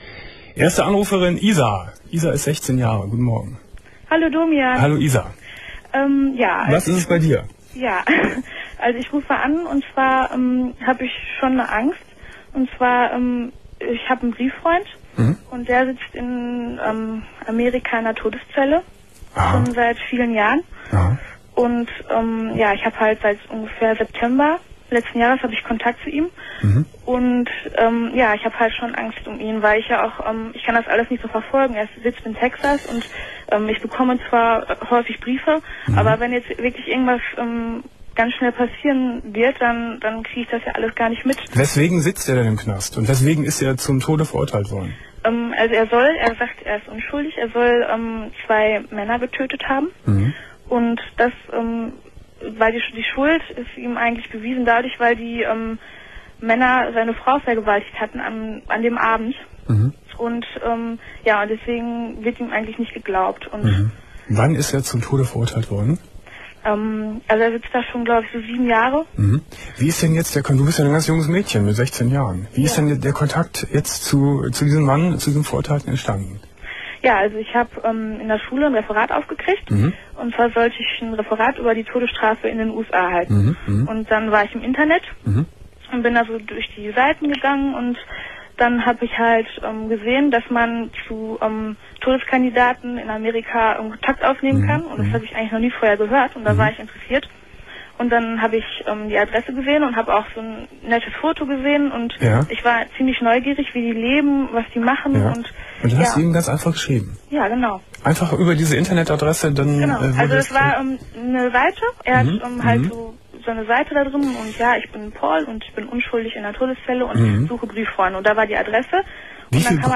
27.06.2001 Domian Thema: Ich kann an nichts anderes mehr denken ~ Domian Talkradio Archiv Podcast